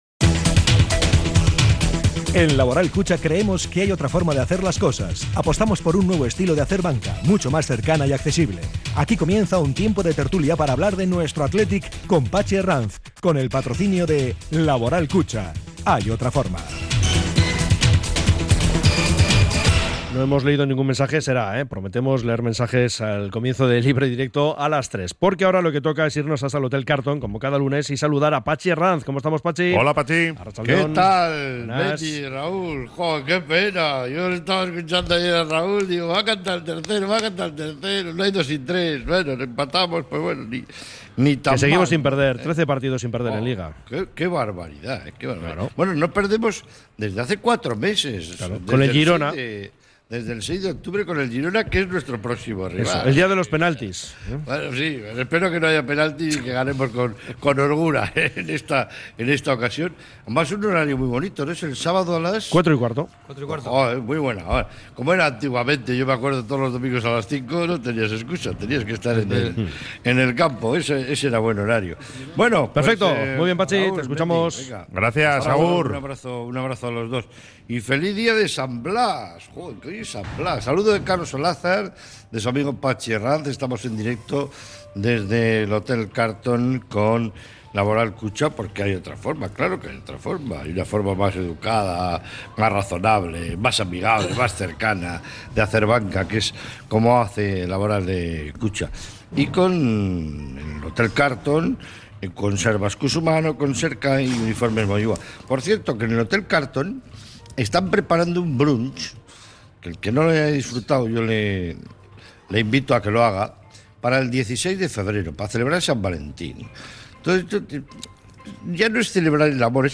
La Tertulia Athletic 03-02-25 | Resaca del Betis 2 - 2 Athletic